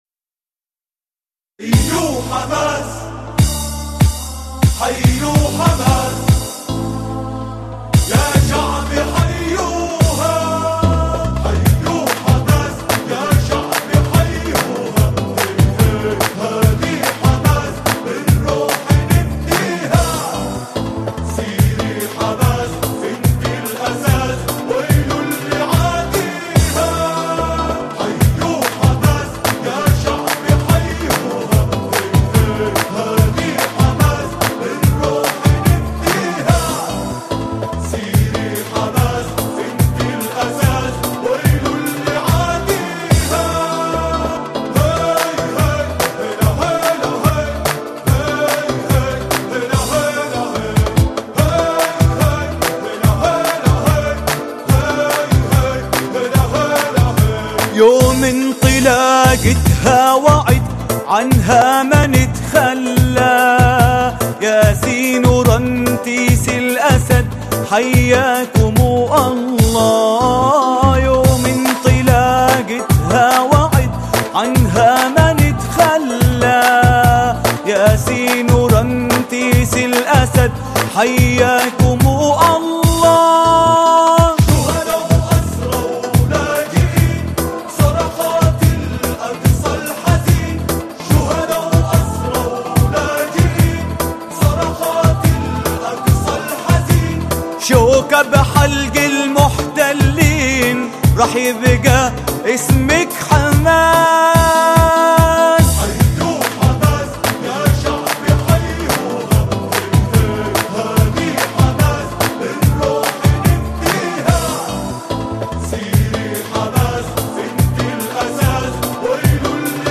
أناشيد فلسطينية